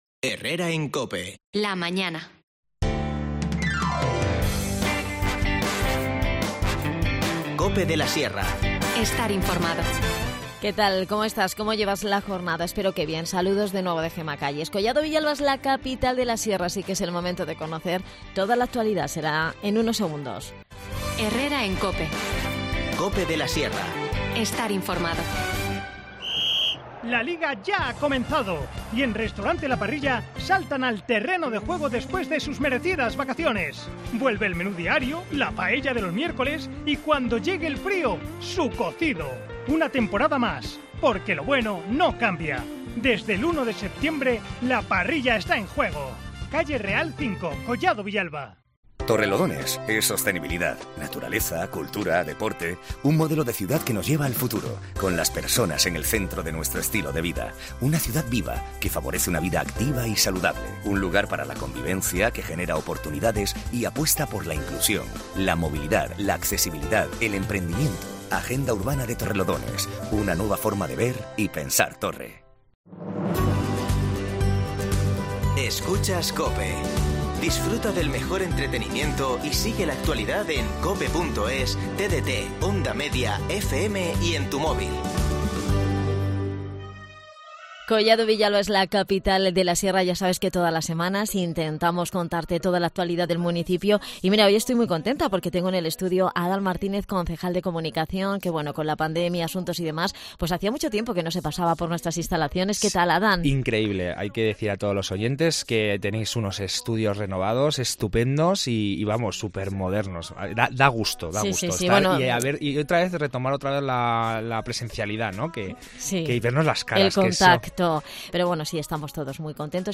Redacción digital Madrid - Publicado el 08 sep 2022, 13:20 - Actualizado 18 mar 2023, 20:21 2 min lectura Descargar Facebook Twitter Whatsapp Telegram Enviar por email Copiar enlace Adan Martínez, concejal de Comunicación, nos visita para hablar de toda la actualidad de Collado Villalba, Capital de la Sierra. Hablamos sobre la inauguración del curso escolar, la ampliación del plazo de inscripción en el acto de Jura de Bandera para personal civil en Los Belgas hasta el próximo 15 de septiembre, y, sobre el distintivo de calidad y seguridad que ha obtenido el Área de Formación y Empleo en sus programas de inserción laboral.